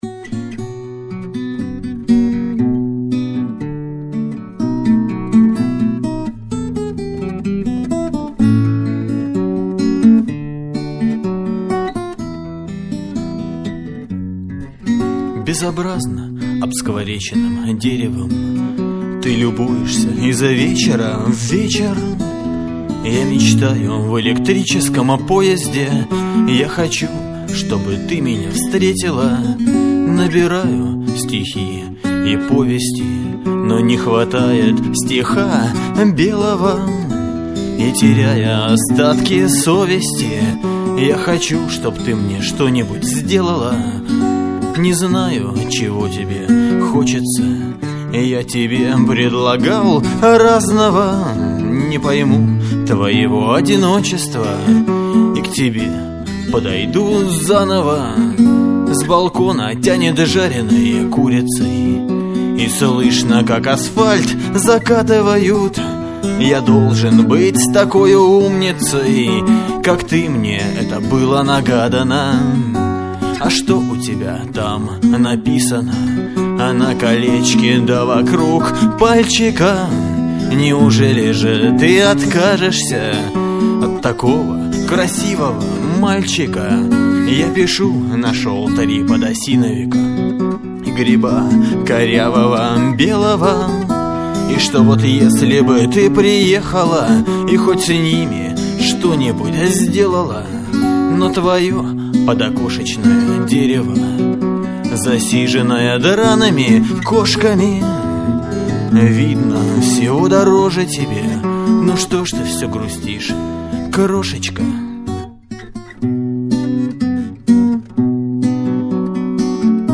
112 kbps, stereo, studio sound. audio live